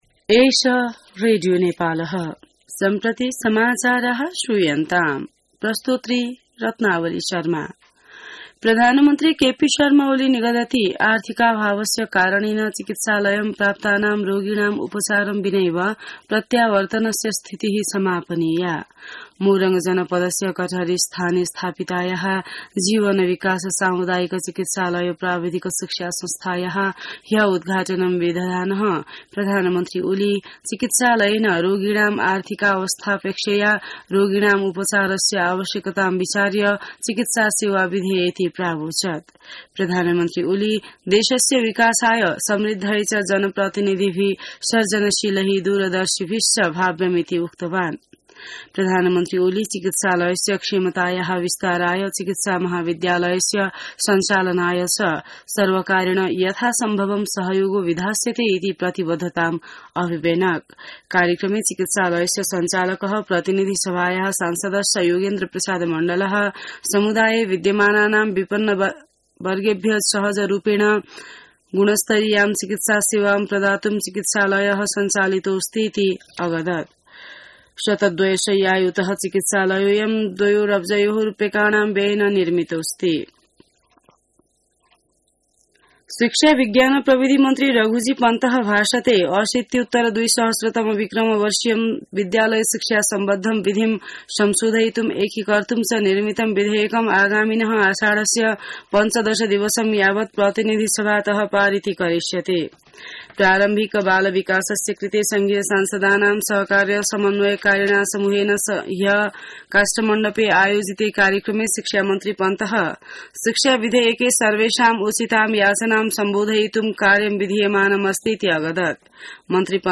संस्कृत समाचार : २५ जेठ , २०८२